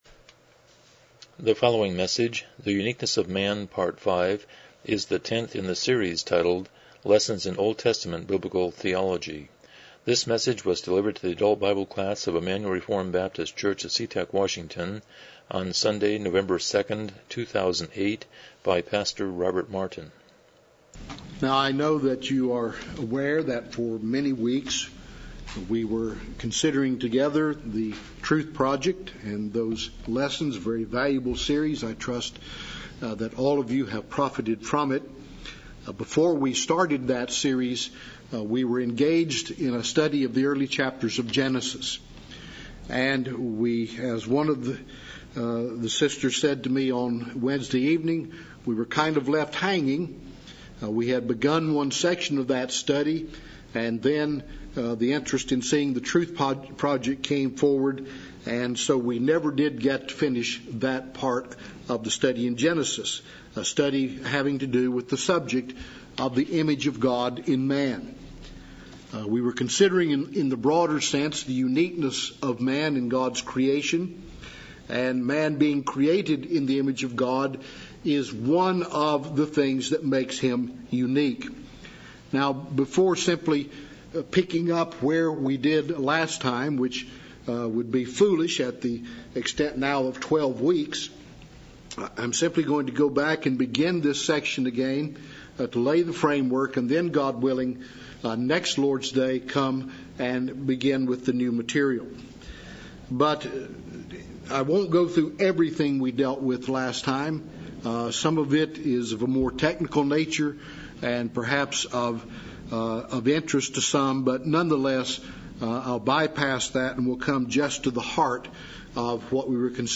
Lessons in OT Biblical Theology Service Type: Sunday School « 46 Chapter 6.2-6.4